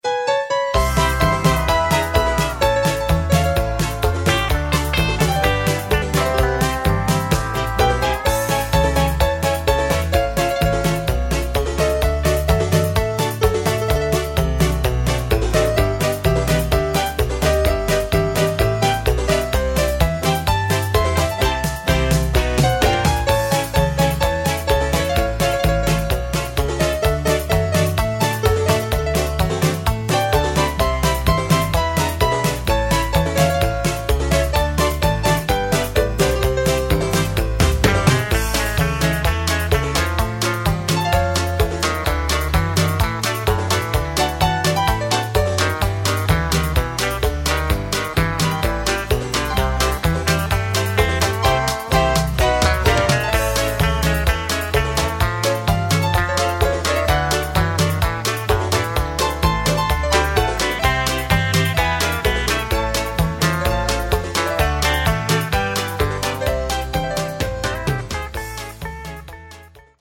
B track has more melody